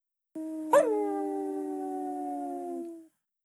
Wolf Death Sound.wav